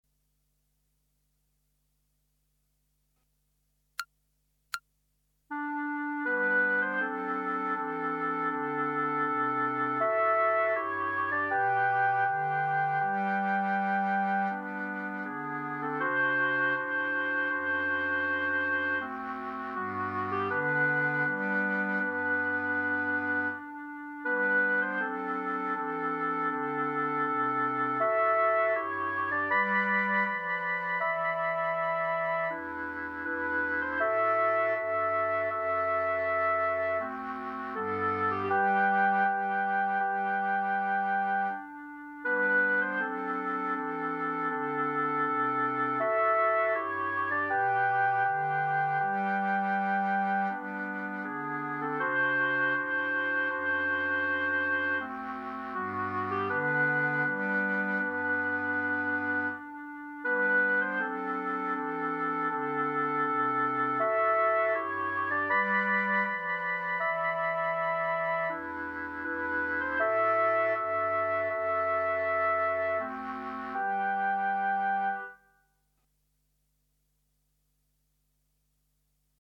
Clarinet Ensemble